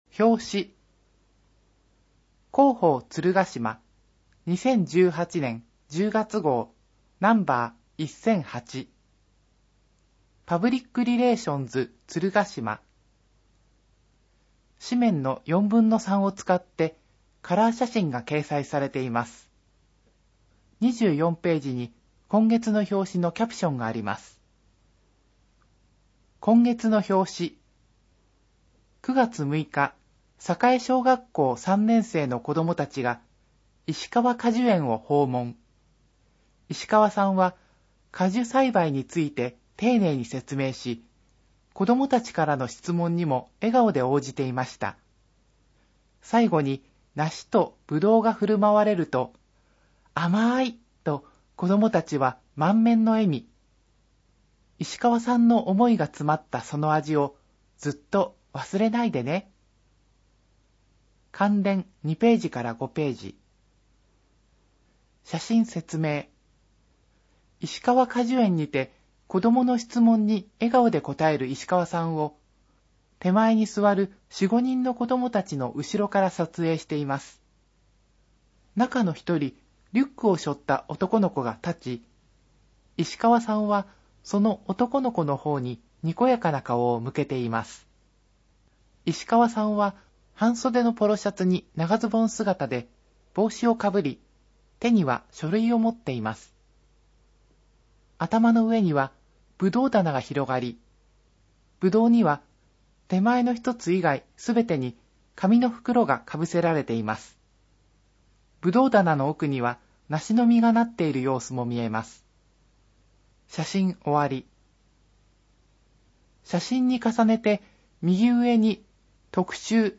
声の広報つるがしまは、「鶴ヶ島音訳ボランティアサークルせせらぎ」の皆さんが「広報つるがしま」の内容を音訳し、「デイジー鶴ヶ島」の皆さんがデイジー版CDを製作して、目の不自由な方々へ配布をしています。